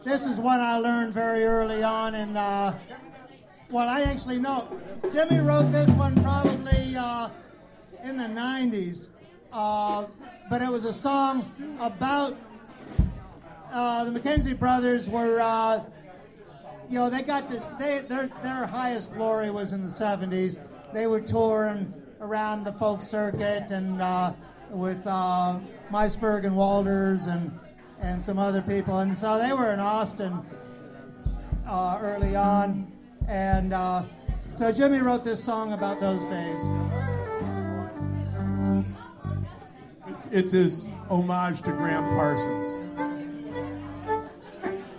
- Memorial concert at Bird's -
These links are to the recording of the show from my Tascam DR-07 that was mounted right in front of the monitor.